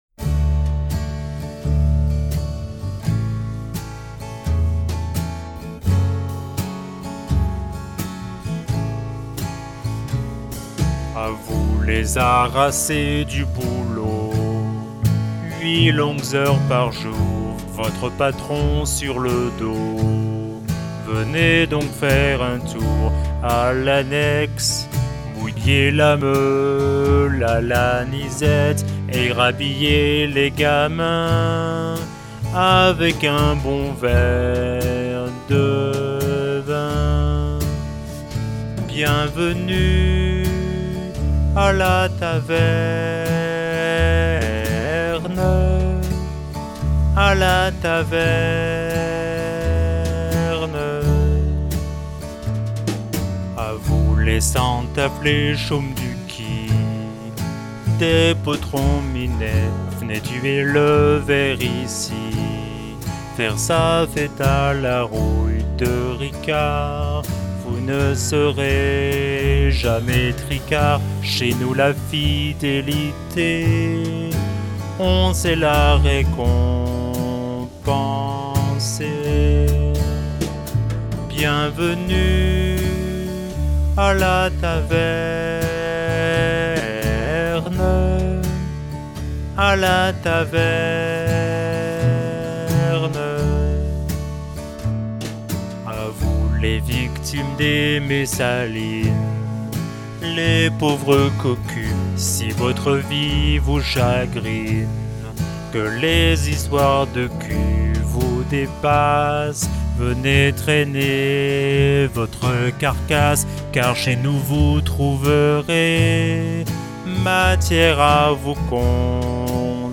Une idée originale, tout à fait dans le thème ; par contre, le mix n'est vraiment pas terrible et visiblement, la compression écrase les paroles, parfois inintelligibles !
Pourquoi pas , une petite balade pop folk ...harmoniquement juste , mélodiquement simple mai viable en revanche pourquoi diable /uzi l'autotuner sur la voix ?!